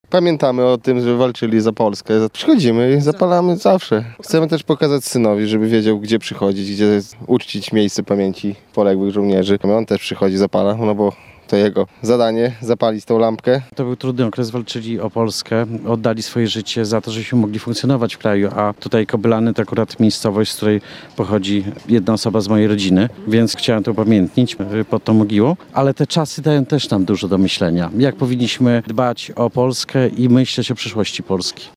– Jesteśmy tutaj zawsze co roku, aby oddać hołd żołnierzom – mówią mieszkańcy Białej Podlaskiej.